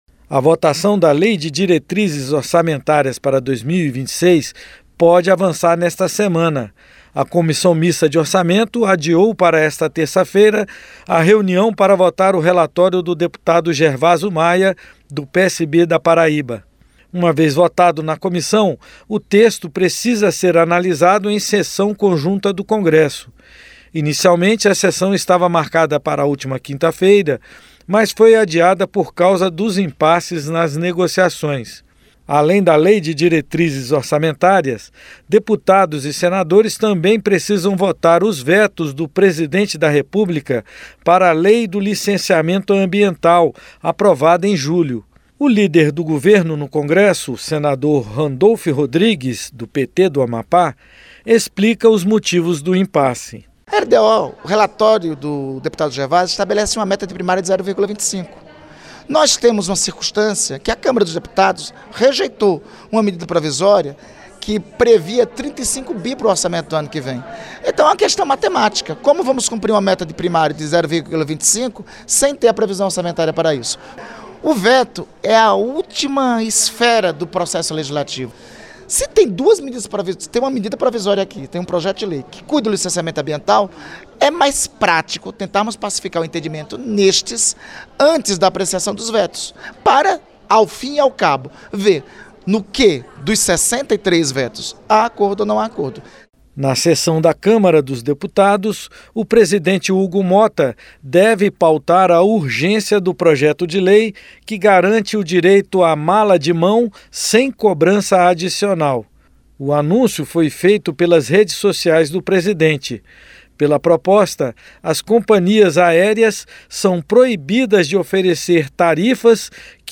A VOTAÇÃO DA LEI DE DIRETRIZES ORÇAMENTÁRIAS PODE AVANÇAR NESTA SEMANA NO CONGRESSO. NO PLENÁRIO DA CÂMARA, O PRESIDENTE HUGO MOTTA JÁ SINALIZOU QUE DEVE COLOCAR EM PAUTA A URGÊNCIA PARA O PROJETO QUE PROÍBE A COBRANÇA DE BAGAGEM DE MÃO PELS COMPANHIAS AÉREAS. A REPORTAGEM